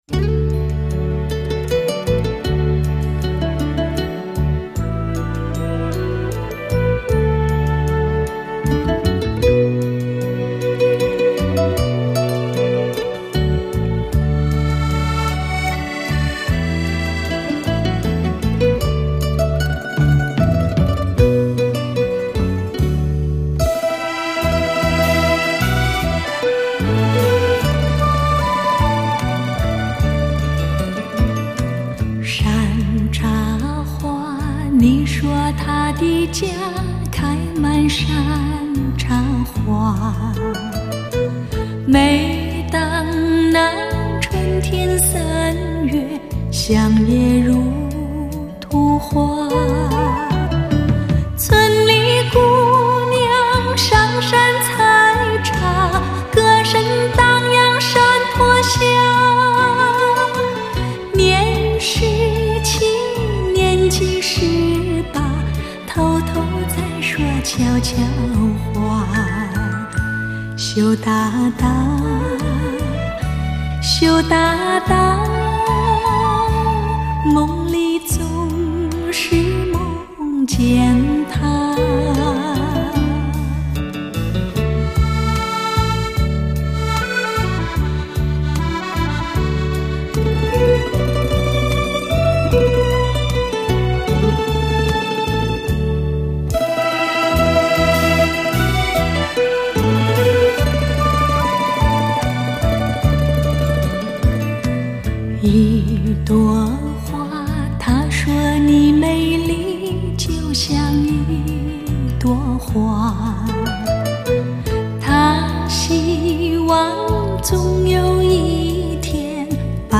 低音质 mp3